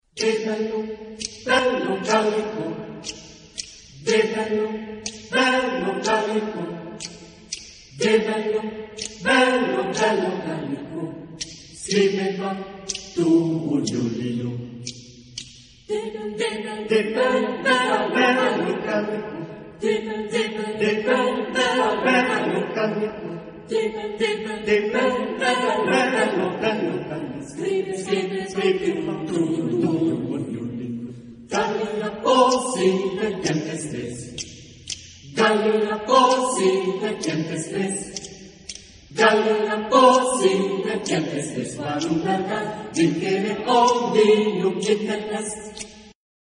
Genre-Style-Forme : Profane ; Chanson
Instrumentation : Percussions
Instruments : Claquements de doigts
Tonalité : la majeur ; la mineur